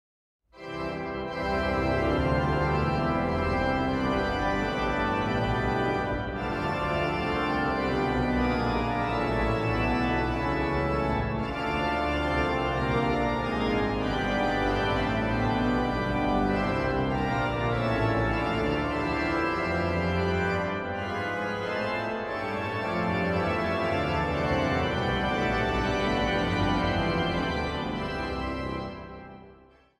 sopraan
bas/bariton
vleugel
orgel
dwarsfluit/panfluit
Zang | Mannenkoor